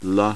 All Pronunciation examples are taken from the online audio companion to Peter Ladefoged's "A Course in Phonetics" Textbook.
Consonants
Consonant Pronunciation Example Translation